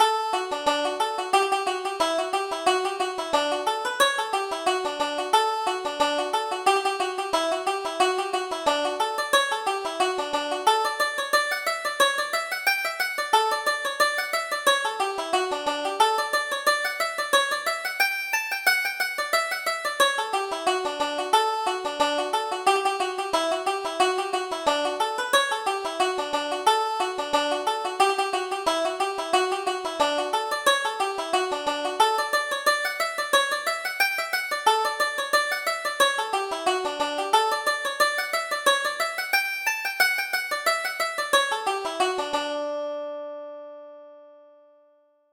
Reel: The Bantry Lasses